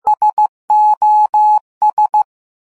Very simple morse code word
sos.mp3